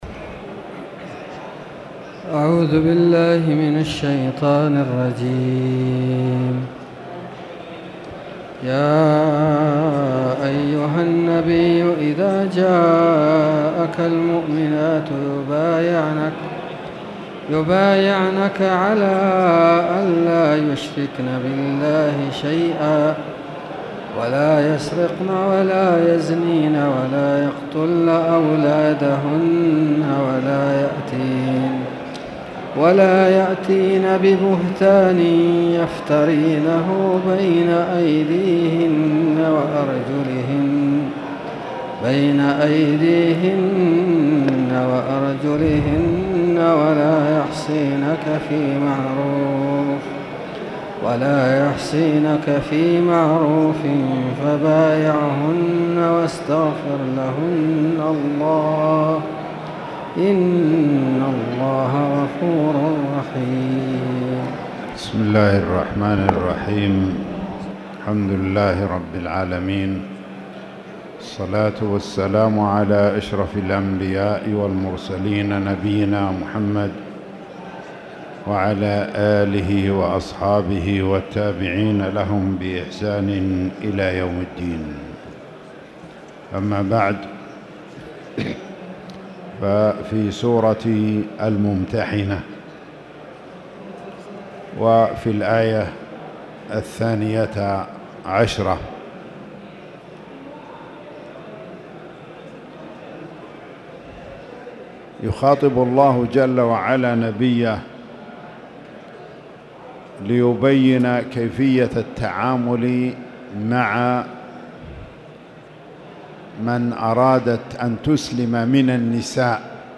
تاريخ النشر ١٩ ربيع الأول ١٤٣٨ هـ المكان: المسجد الحرام الشيخ